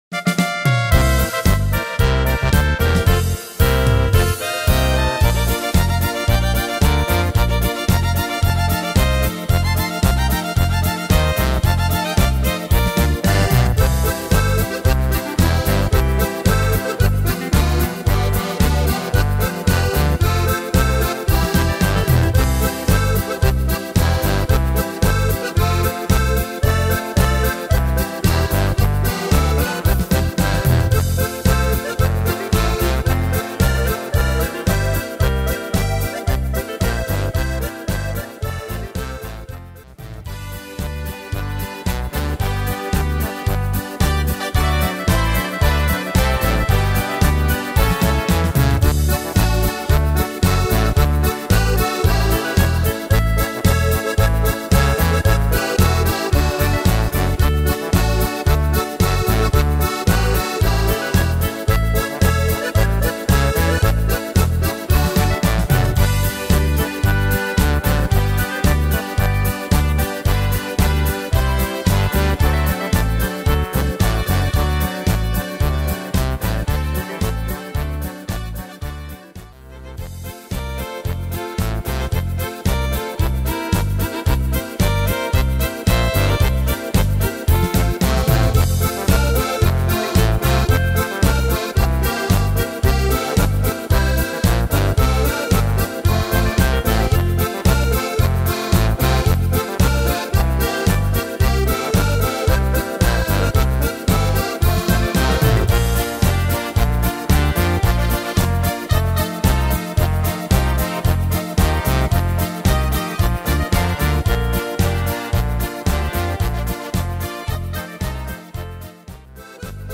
Tempo: 112 / Tonart: Bb-Dur